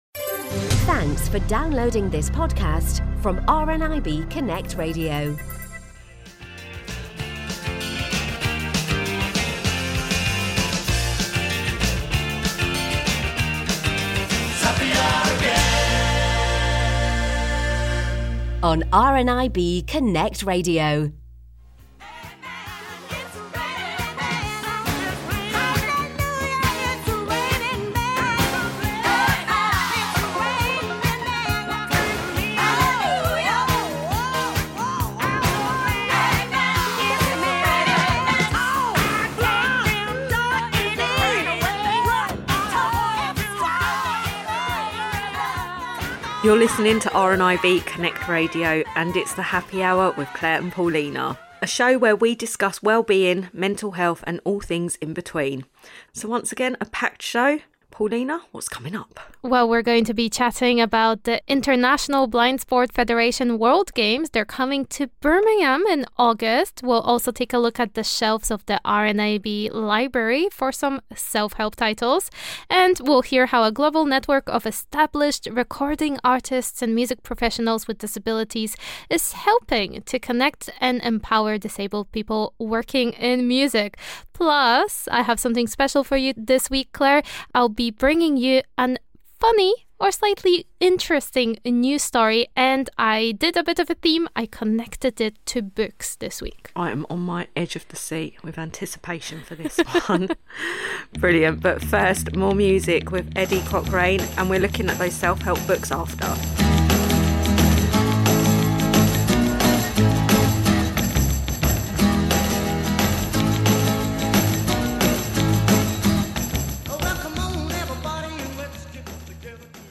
With a good dose of great music, random chat, and great interviews, it’s time for the latest episode of the Happy Hour.